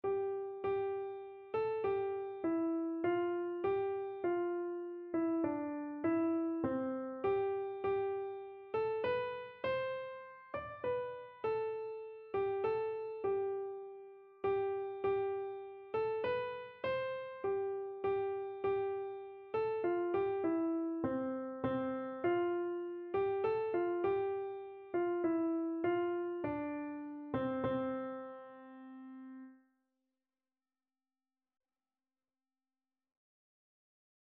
Christian Christian Keyboard Sheet Music We Gather Together
Free Sheet music for Keyboard (Melody and Chords)
C major (Sounding Pitch) (View more C major Music for Keyboard )
3/4 (View more 3/4 Music)
Keyboard  (View more Intermediate Keyboard Music)
Traditional (View more Traditional Keyboard Music)